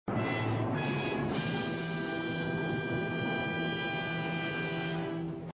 dramatic-6s.mp3